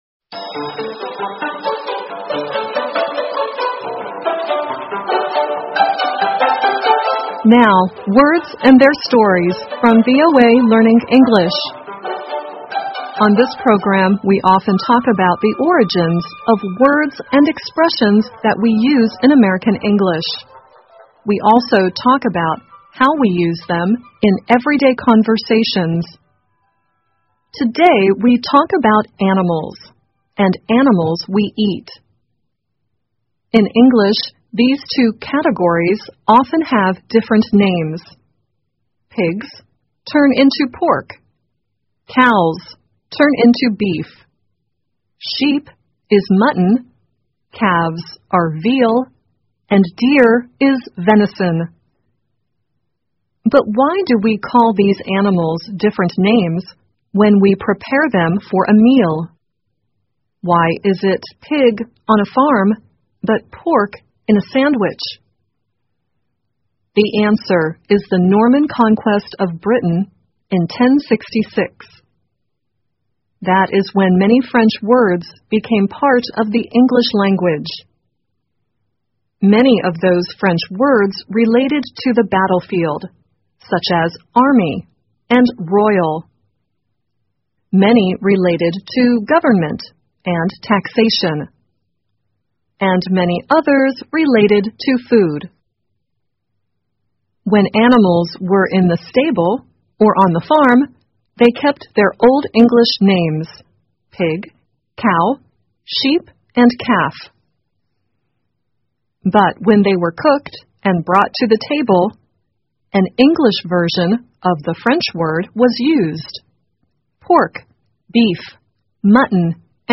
VOA慢速英语--Pig or Pork? Cow or Beef？ 听力文件下载—在线英语听力室